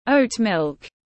Sữa yến mạch tiếng anh gọi là oat milk, phiên âm tiếng anh đọc là /ˈəʊt ˌmɪlk/